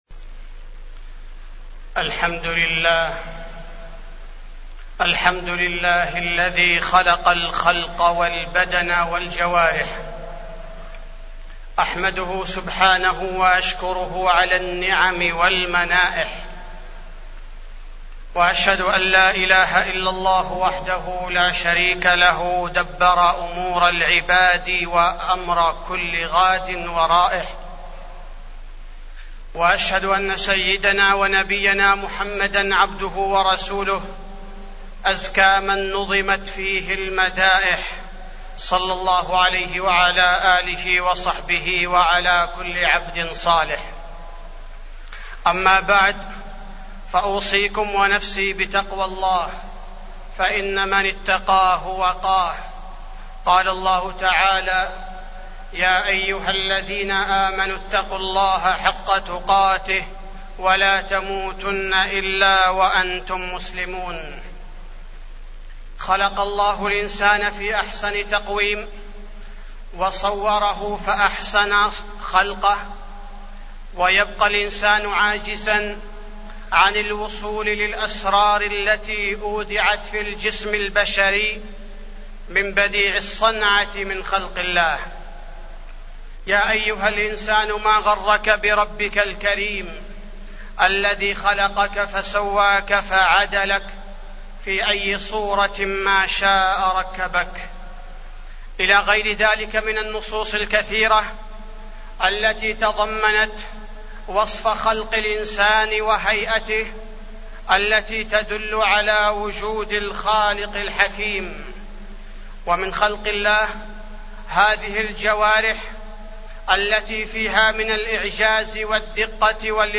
تاريخ النشر ٤ جمادى الأولى ١٤٢٩ هـ المكان: المسجد النبوي الشيخ: فضيلة الشيخ عبدالباري الثبيتي فضيلة الشيخ عبدالباري الثبيتي نعمة الجوارح في خلق الإنسان